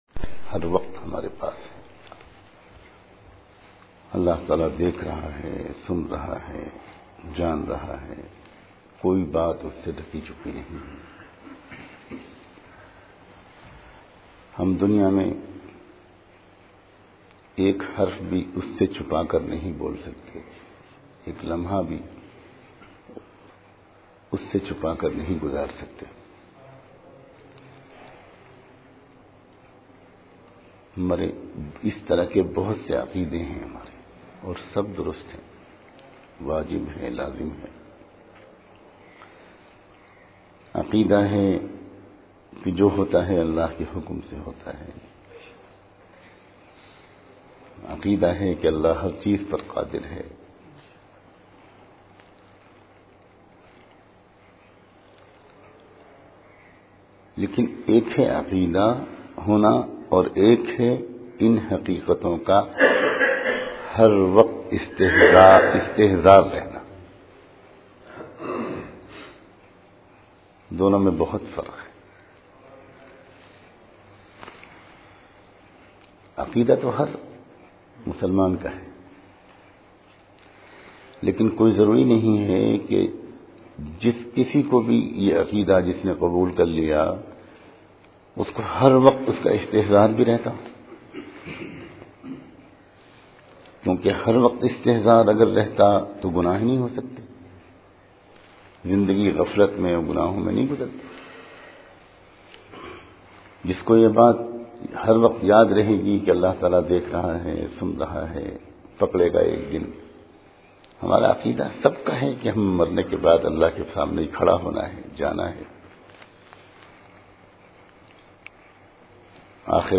aalah ka istehzar bayan MP3